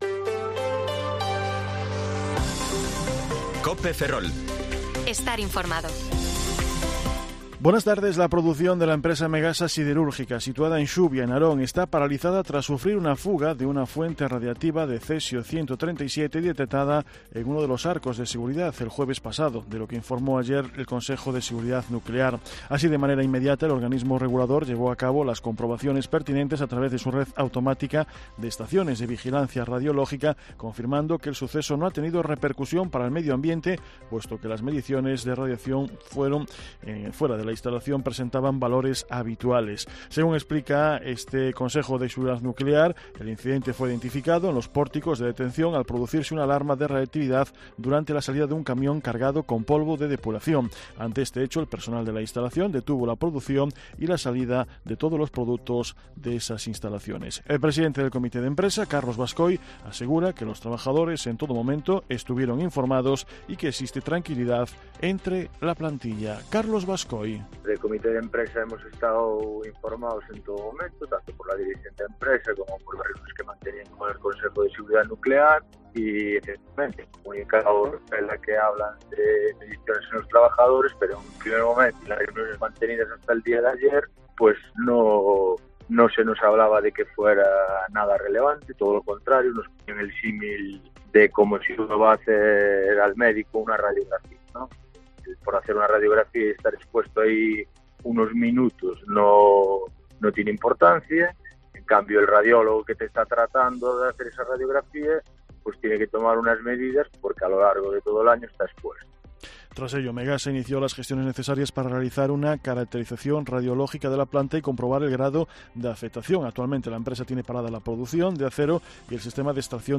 Informativo Mediodía COPE Ferrol 14/6/2023 (De 14,20 a 14,30 horas)